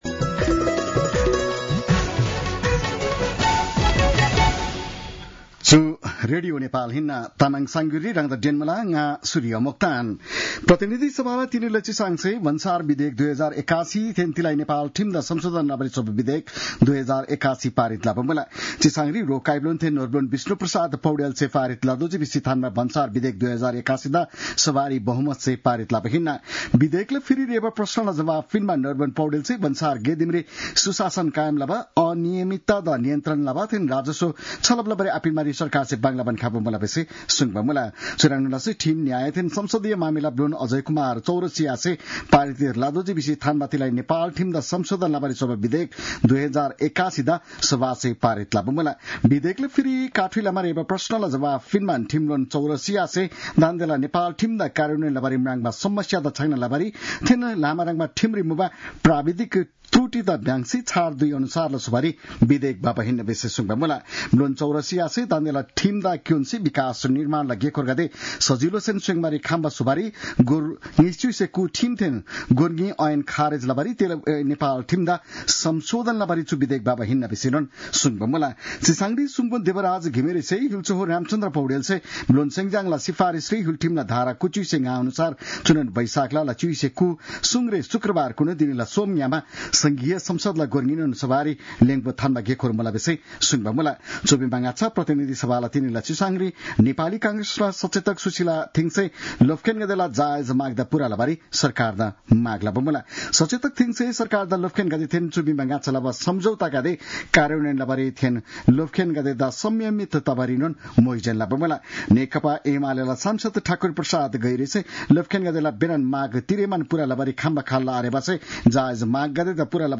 तामाङ भाषाको समाचार : १६ वैशाख , २०८२